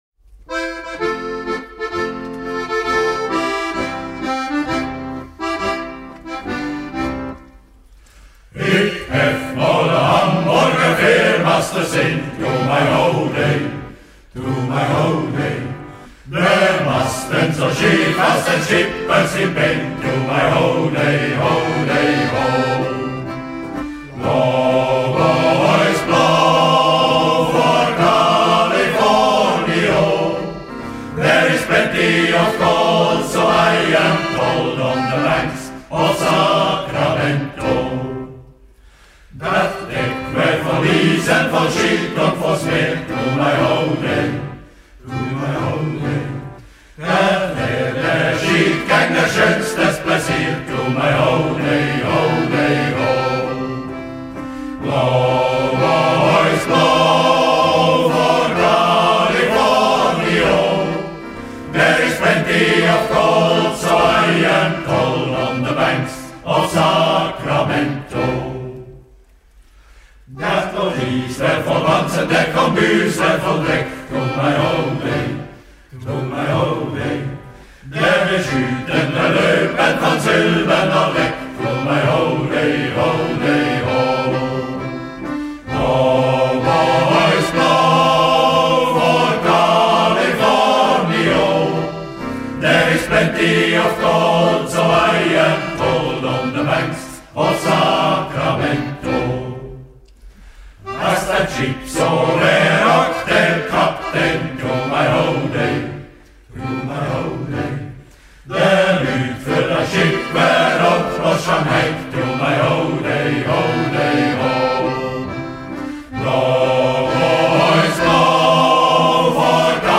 Die Shantycrew Tribschenhorn Luzern ist ein traditioneller Shanty Chor aus der Zentralschweiz, der seit vielen Jahren mit maritimen Liedern und Seemannsshantys für unvergessliche Stimmung sorgt.
Shantys sind traditionelle Arbeits- und Seemannslieder, die früher an Bord von Segelschiffen gesungen wurden.
Der Klang ist kräftig, warm und mitreissend – das Publikum wippt mit, singt mit und taucht für einen Moment in die Welt der Seefahrer ein.